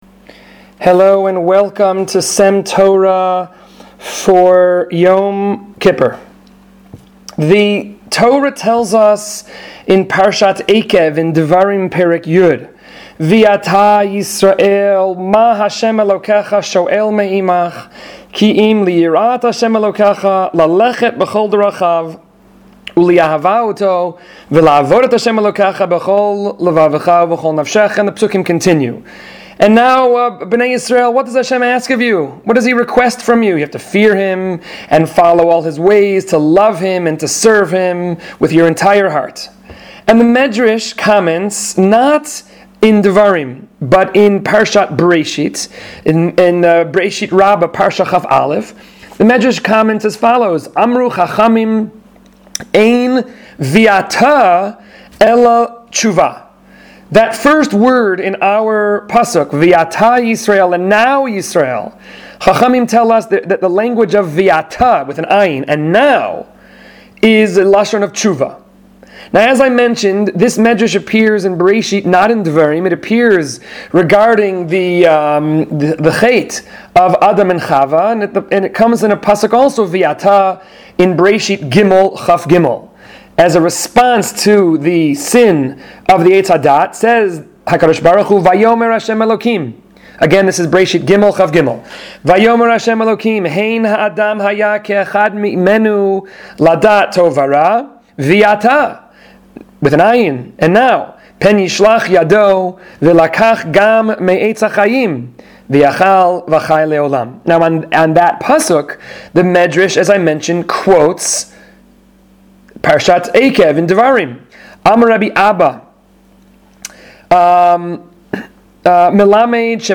S.E.M. Torah is a series of brief divrei Torah delivered by various members of the faculty of Sha�alvim for Women.